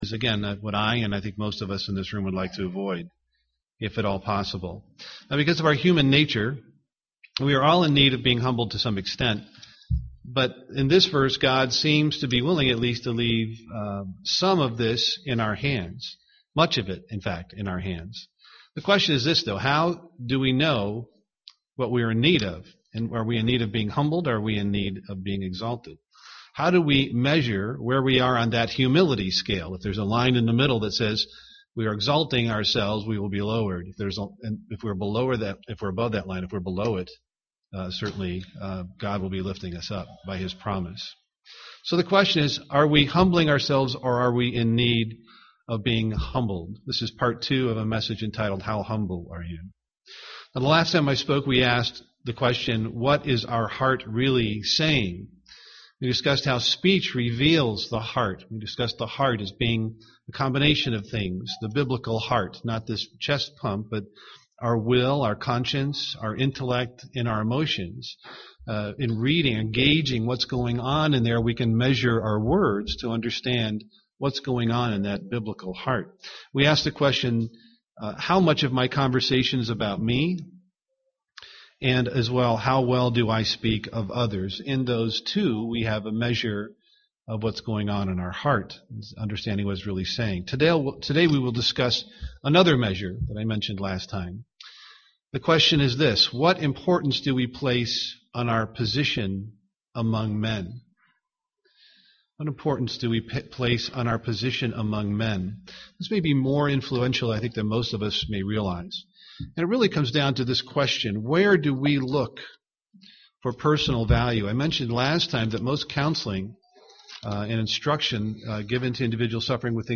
Given in Twin Cities, MN
UCG Sermon Studying the bible?